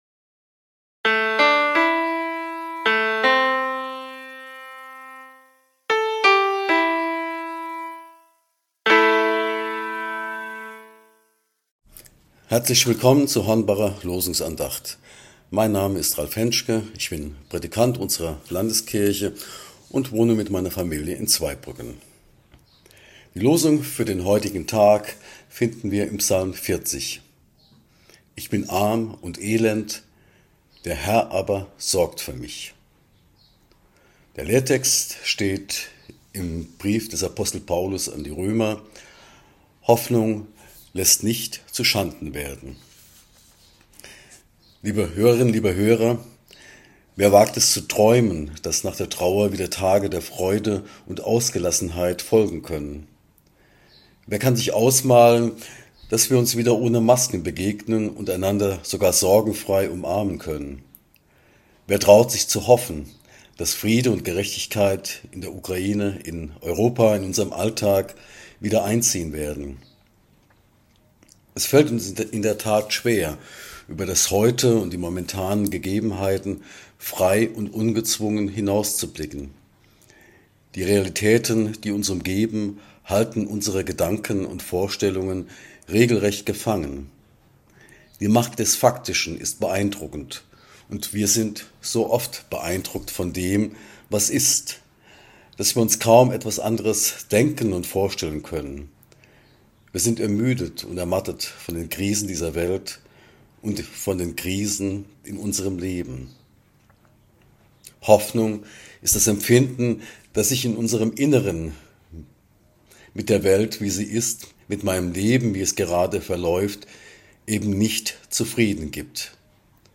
Losungsandacht für Montag, 30.05.2022